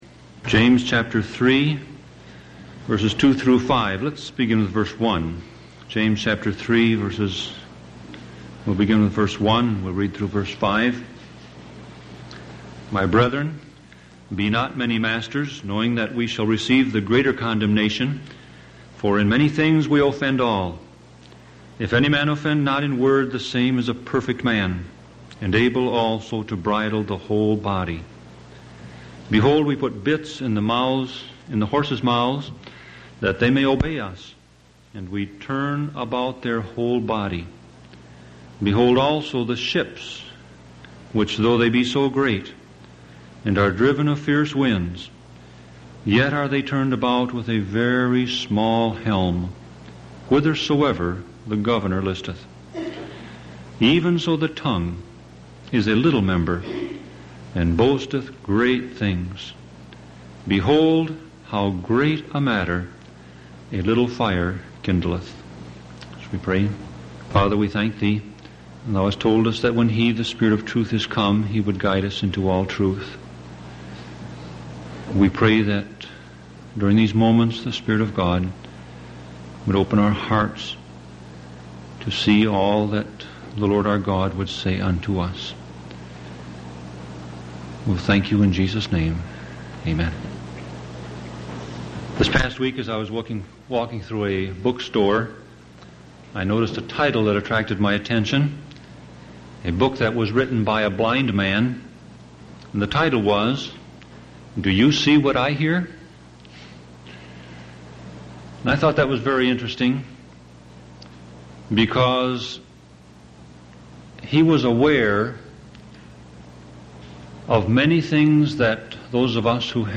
Sermon Audio Passage: James 3:2-5 Service Type